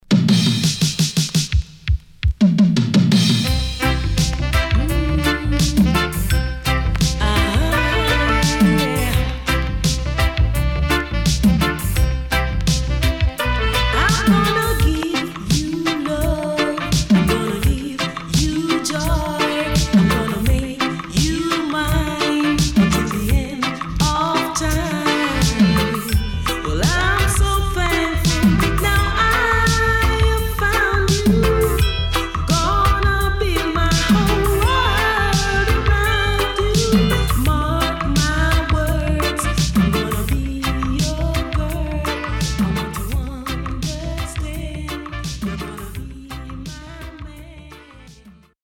HOME > Back Order [DANCEHALL DISCO45]
SIDE B:少しチリ、プチノイズ入りますが良好です。